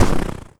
High Quality Footsteps
STEPS Snow, Run 21.wav